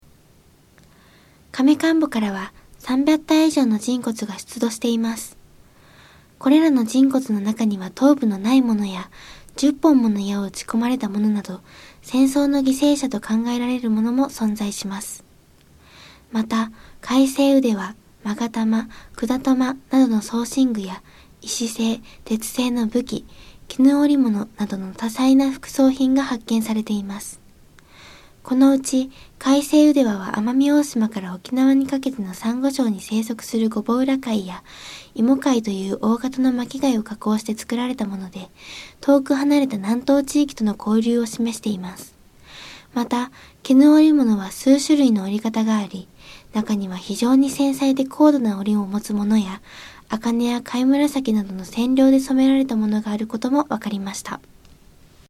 音声ガイド 前のページ 次のページ ケータイガイドトップへ (C)YOSHINOGARI HISTORICAL PARK